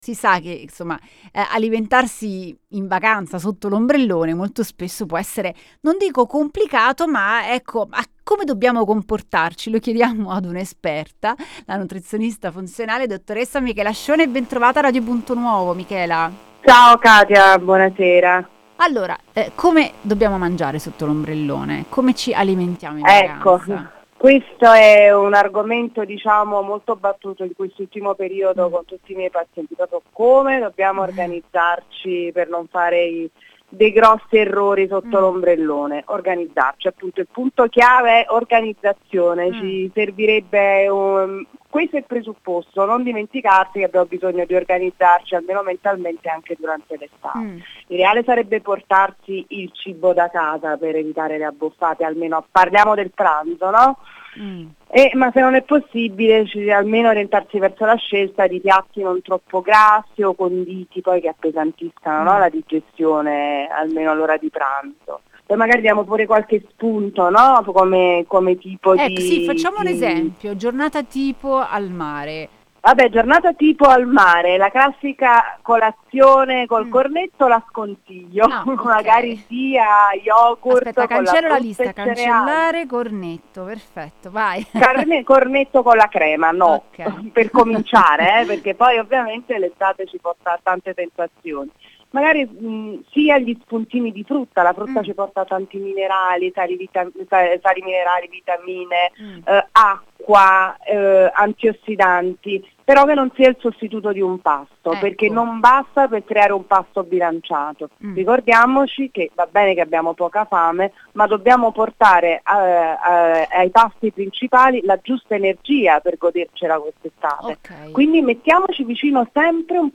nutrizionista funzionale